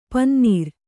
♪ pannīr